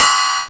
• Crash Cymbal Sound C# Key 07.wav
Royality free crash cymbal sound clip tuned to the C# note. Loudest frequency: 3693Hz
crash-cymbal-sound-c-sharp-key-07-ln9.wav